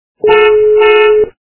» Звуки » Авто, мото » Авто - сигнал
При прослушивании Авто - сигнал качество понижено и присутствуют гудки.
Звук Авто - сигнал